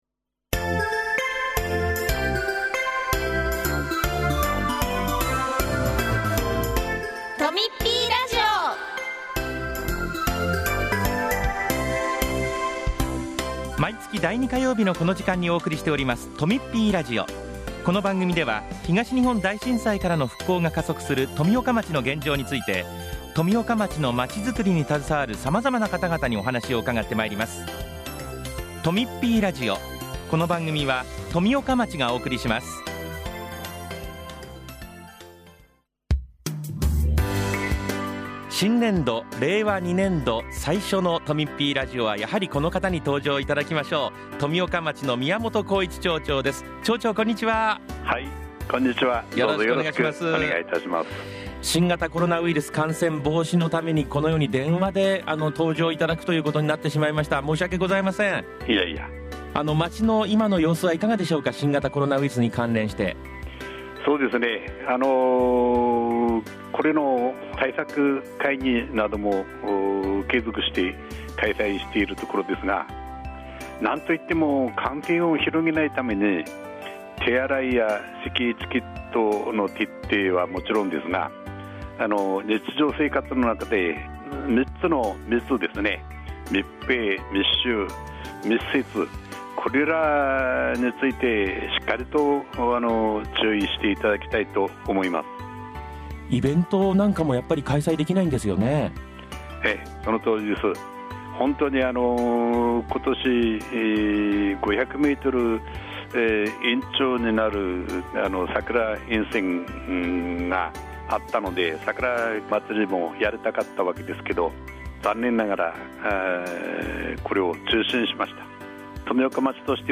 今年度最初の放送は宮本皓一町長が、新型コロナウィルスの感染拡大を受け、現在の富岡町の様子、3月14日の常磐線再開通について、聖火リレー中止について、新年度の取り組みについて、などの内容になっています。